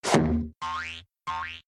teleport_reappear.ogg